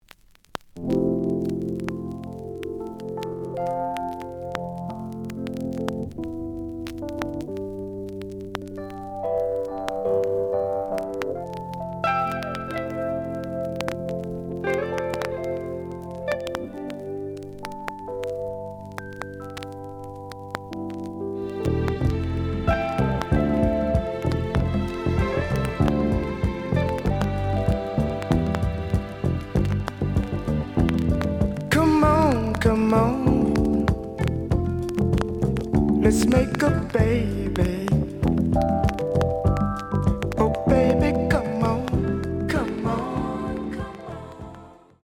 The audio sample is recorded from the actual item.
●Genre: Soul, 70's Soul
Some click noise on A side due to scratches.